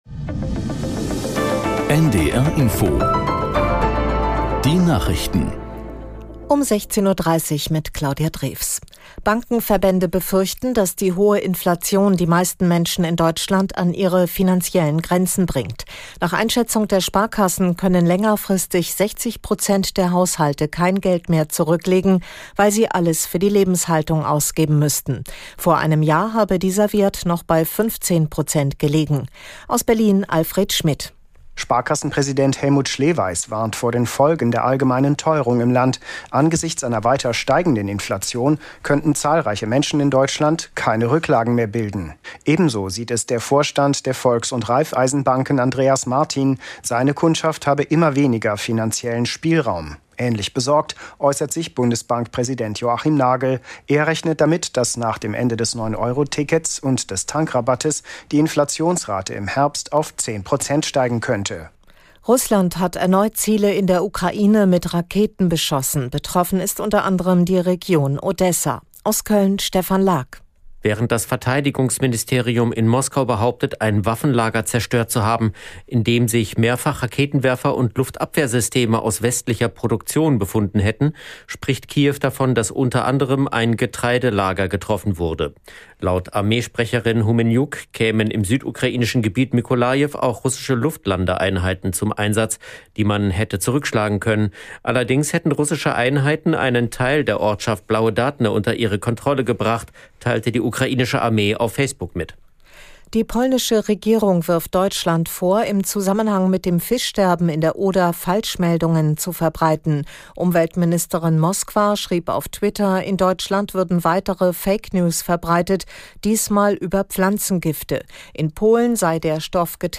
Nachrichten - 21.08.2022